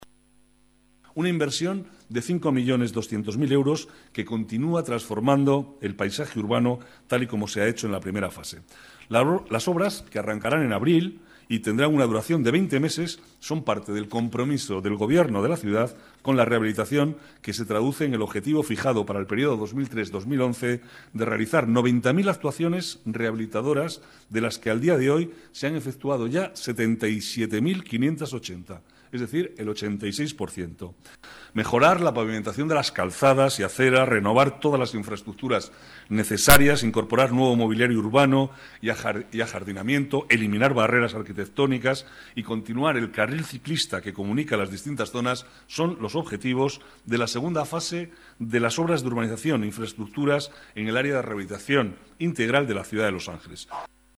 Nueva ventana:Declaraciones de Manuel Cobo, vicealcalde de Madrid